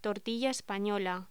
Locución: Tortilla española
voz